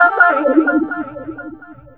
VOX FX 7  -R.wav